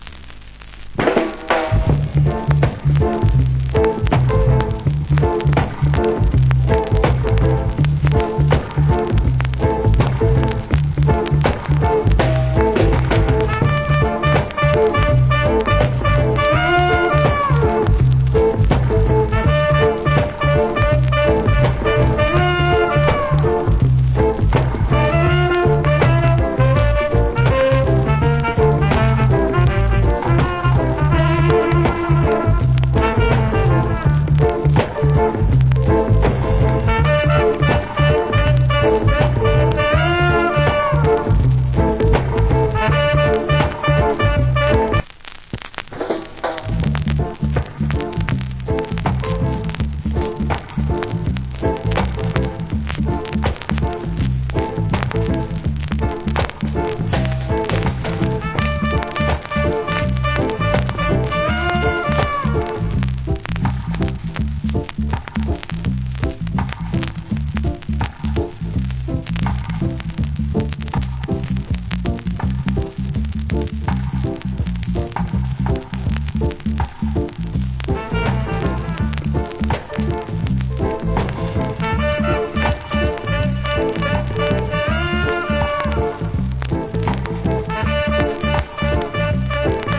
Rock Steady Inst.